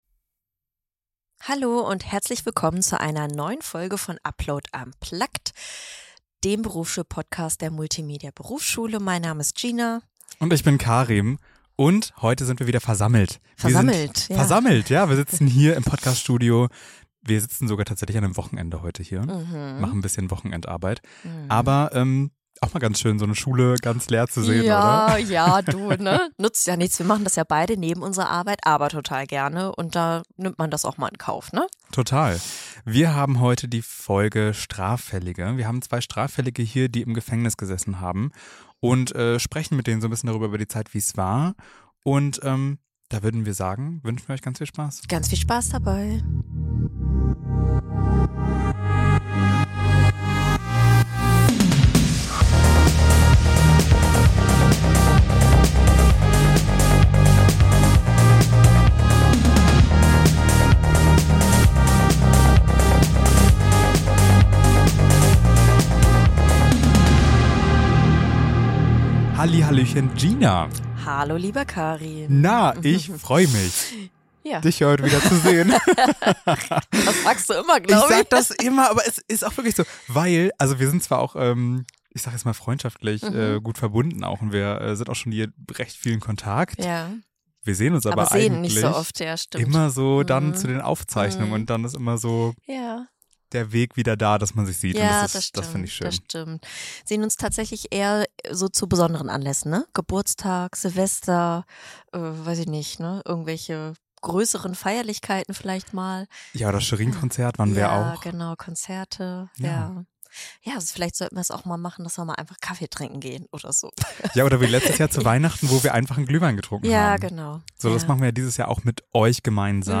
Beschreibung vor 6 Monaten Zwei ehemalige Strafgefangene, zwei bewegende Lebensgeschichten und ein gemeinsames Ziel: Jugendliche vor der schiefen Bahn bewahren.